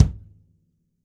• Punchy Kick Drum C Key 131.wav
Royality free kickdrum tuned to the C note. Loudest frequency: 381Hz
punchy-kick-drum-c-key-131-kbv.wav